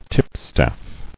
[tip·staff]